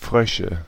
Ääntäminen
Ääntäminen Tuntematon aksentti: IPA: /ˈfʀœʃə/ Haettu sana löytyi näillä lähdekielillä: saksa Käännöksiä ei löytynyt valitulle kohdekielelle. Frösche on sanan Frosch monikko.